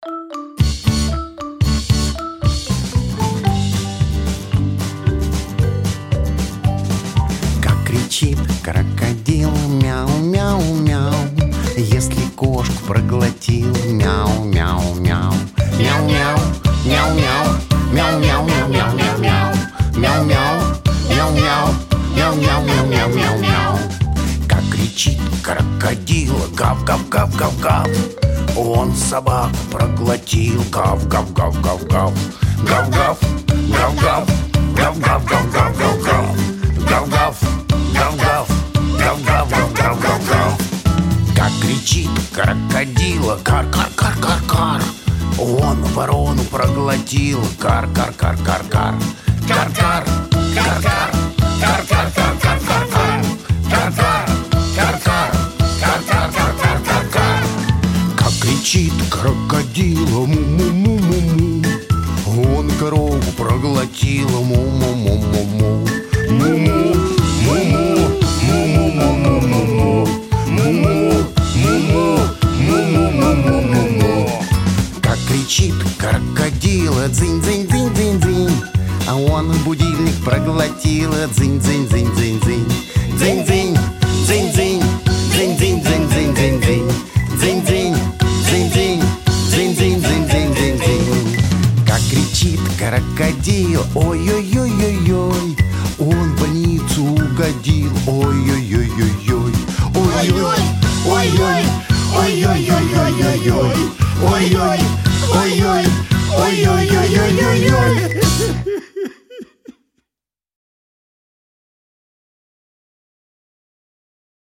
• Качество: Хорошее
• Жанр: Детские песни
шуточная